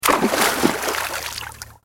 جلوه های صوتی
دانلود صدای آب 36 از ساعد نیوز با لینک مستقیم و کیفیت بالا